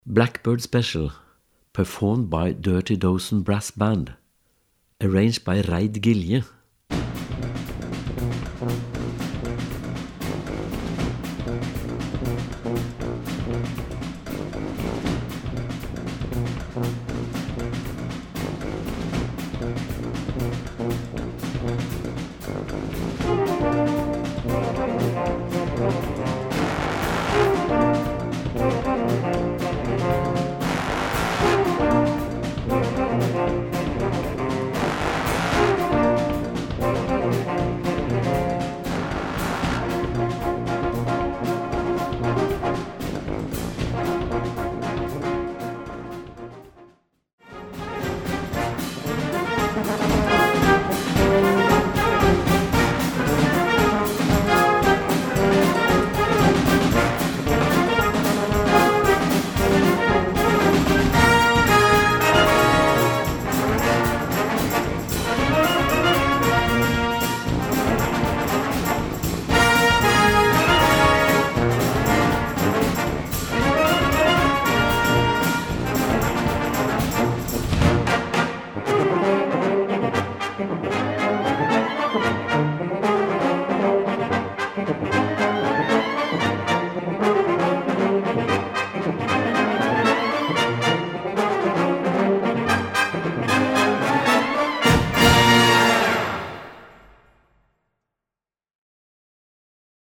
Gattung: für Blasorchester
Besetzung: Blasorchester
The most important is the groove of the piece.